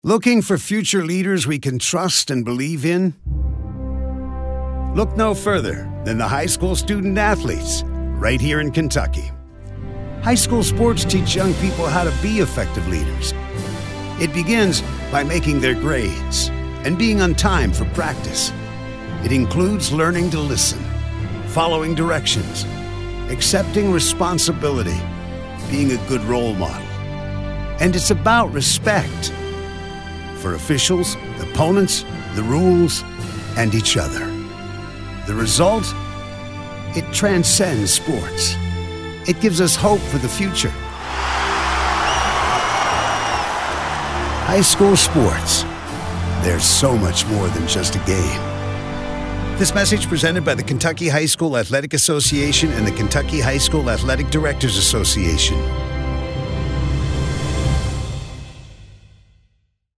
18-19 Radio – Public Service Announcements